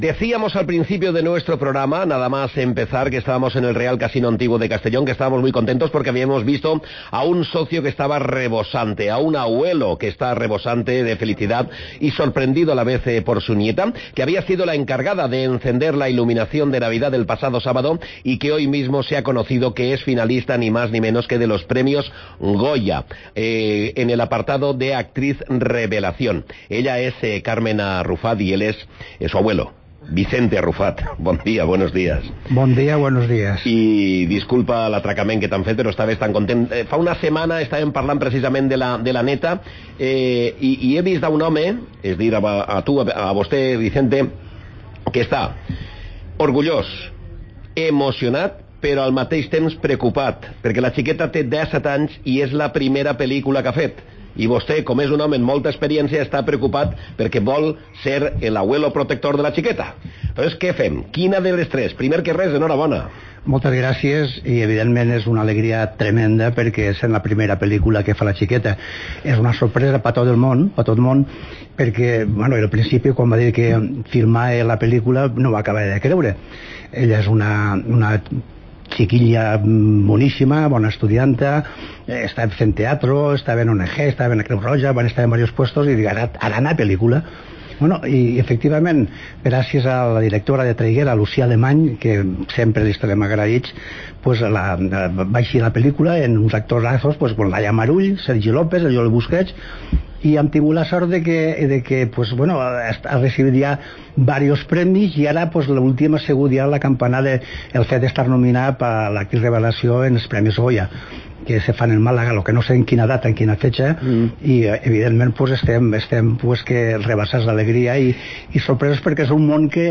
ha celebrado la nominación en los micrófonos de la Cadena COPE donde se ha mostrado feliz por la nominación de su nieta en la que es la primera película en la que participa.